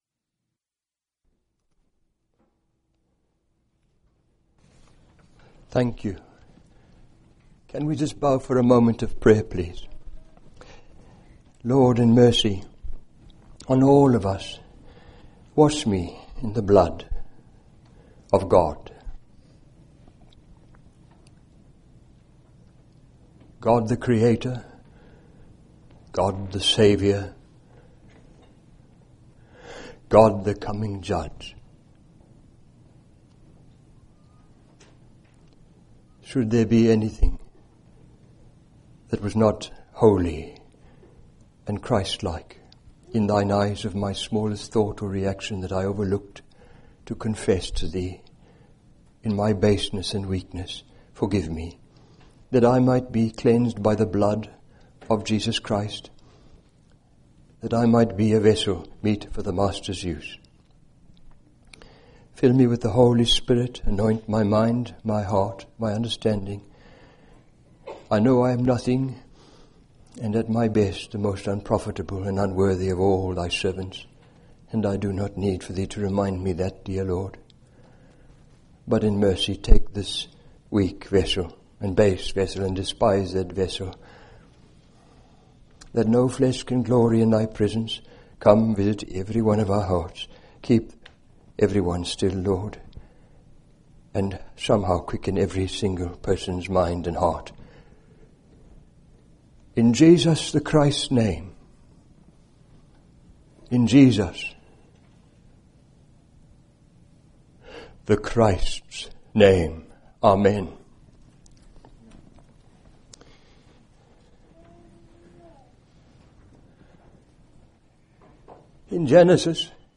In this sermon, the preacher focuses on the second coming of the Lord Jesus and the ultimate defeat of Satan. He quotes over 400 verses, discussing topics such as the revelation of Satan, Armageddon, the antichrist, and God's eventual triumph. The preacher emphasizes the importance of recognizing Satan's hold on the world and the need to resist his temptations.